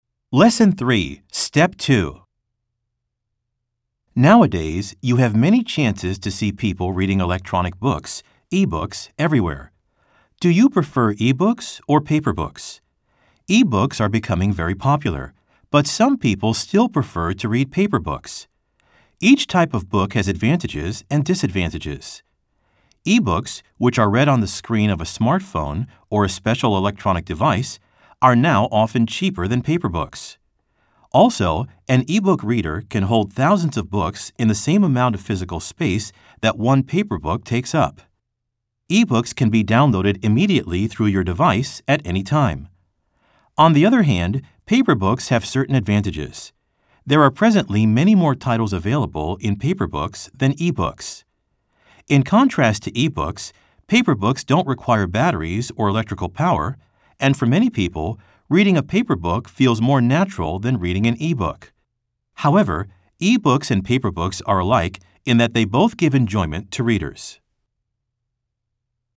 WORKBOOKで一度取り組んだ，初見ではない英文なので，速めのスピードで読み上げられています。